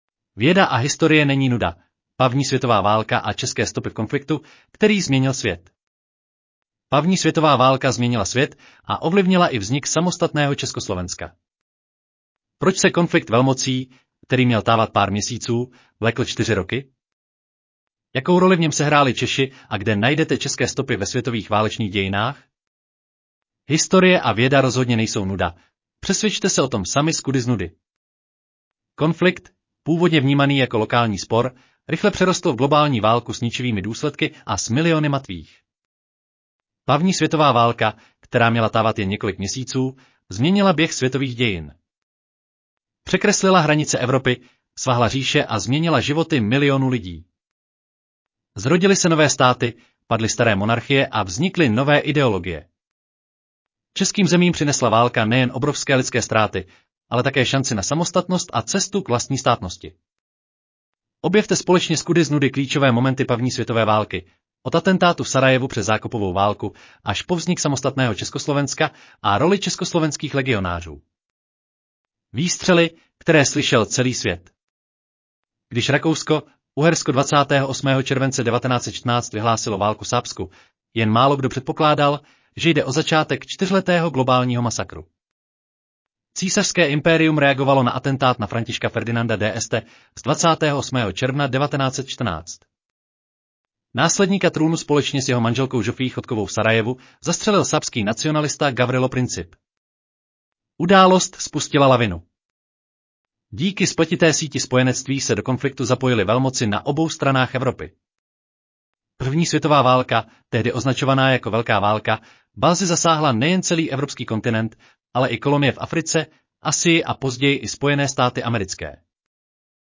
Audio verze článku Věda a historie není nuda: První světová válka a české stopy v konfliktu, který změnil svět